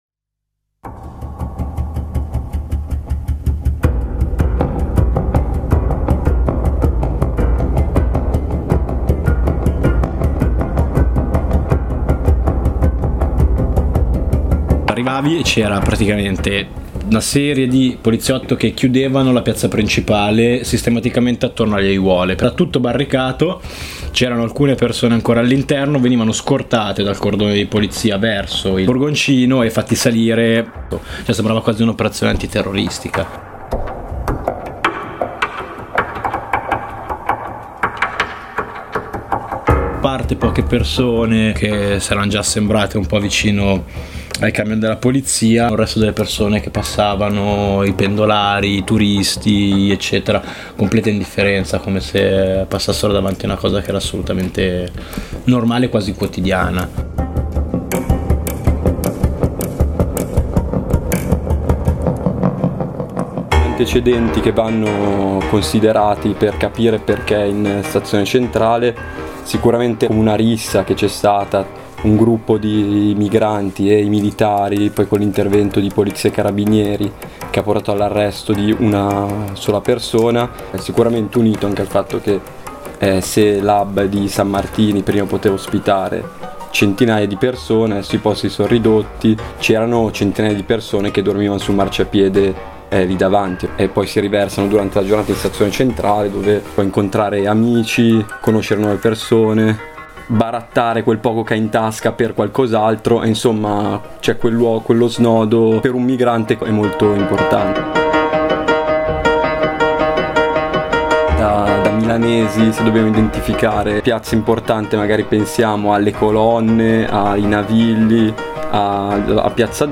Ne parliamo con alcuni compagni dell’assemblea noborders milano.